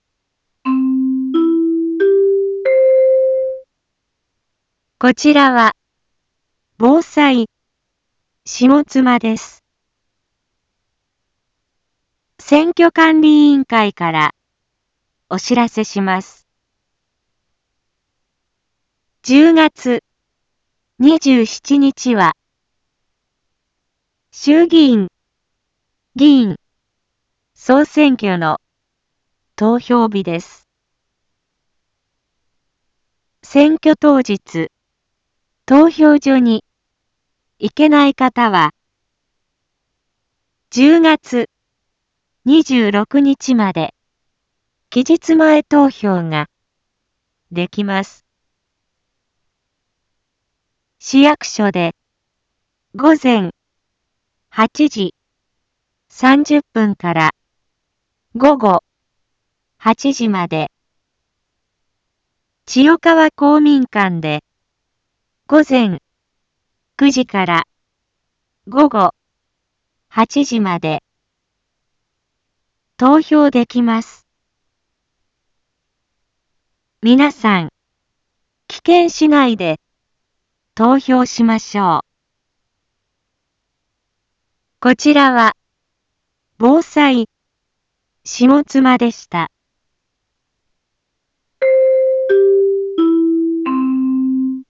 一般放送情報
Back Home 一般放送情報 音声放送 再生 一般放送情報 登録日時：2024-10-20 18:31:40 タイトル：衆議院議員総選挙の啓発（期日前投票期間） インフォメーション：こちらは、ぼうさいしもつまです。